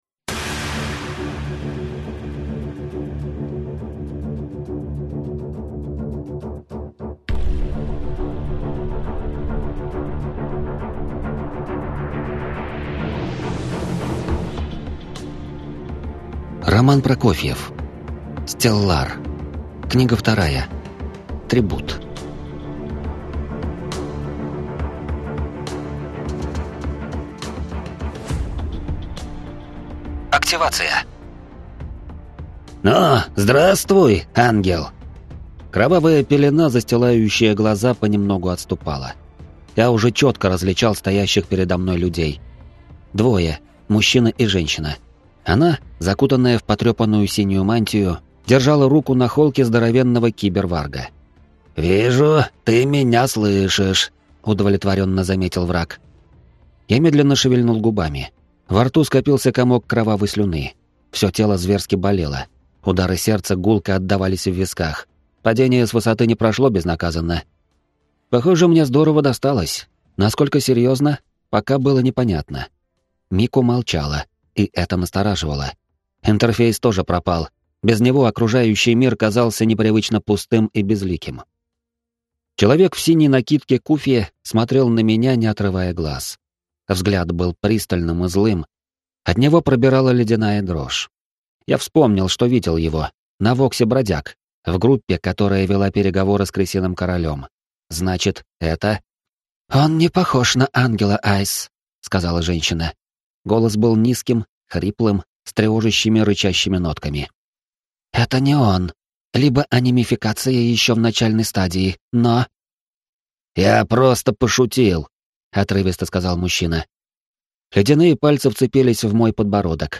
Аудиокнига Стеллар. Трибут | Библиотека аудиокниг